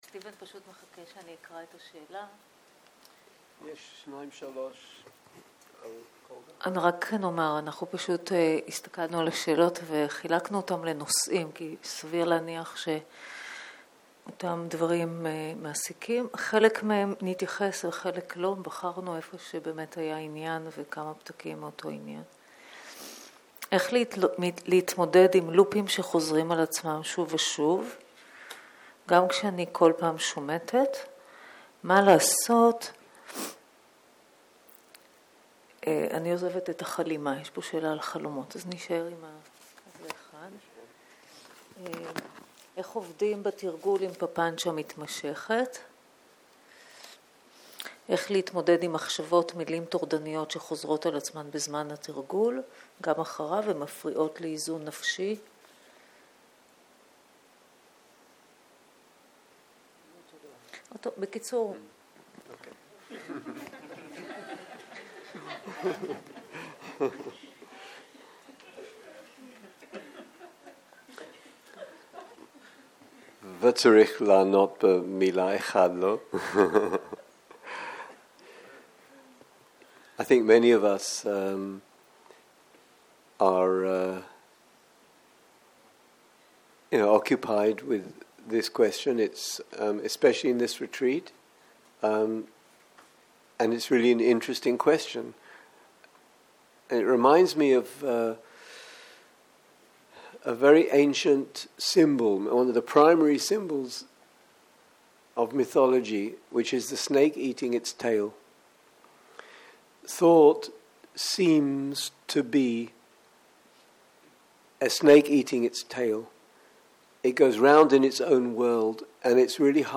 שאלות ותשובות - ערב
סוג ההקלטה: שאלות ותשובות